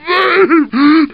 zombie_voice_idle10.mp3